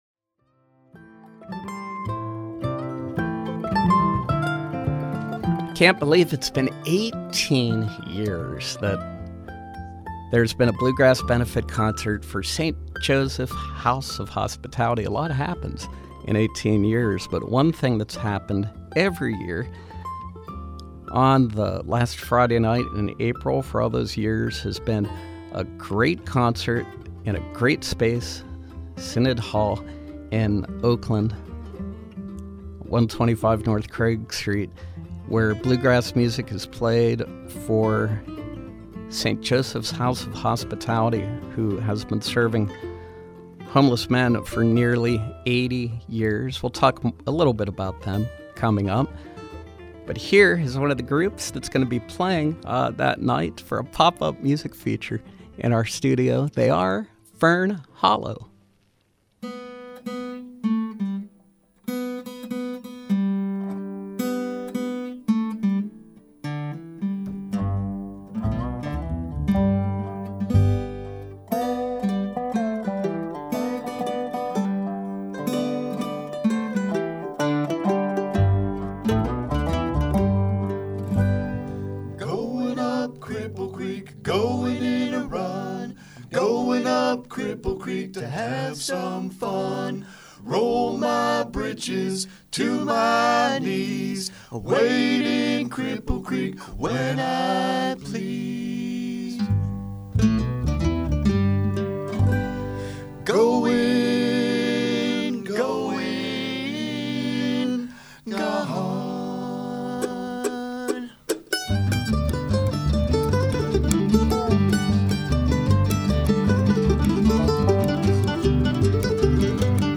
Bluegrass